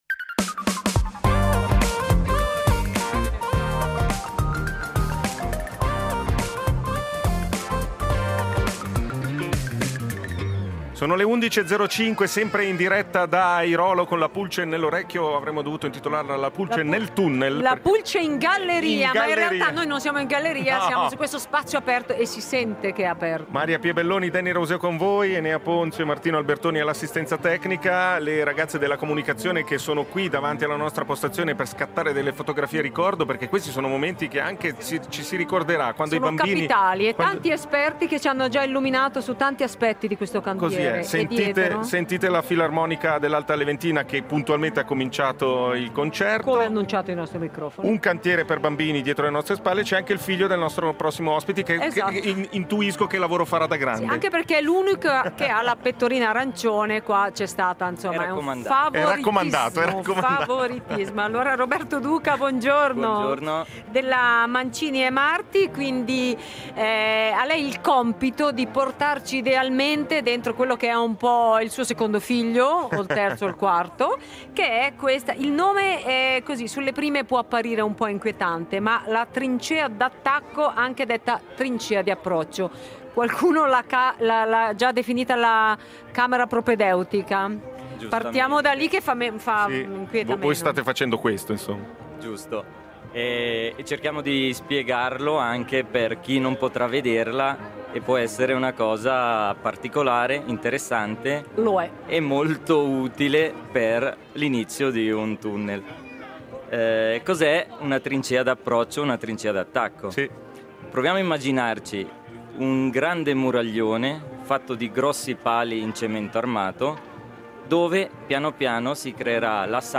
Rete Uno in diretta da Airolo per la Giornata delle porte Aperte sul cantiere per la realizzazione del secondo tubo della galleria autostradale del San Gottardo.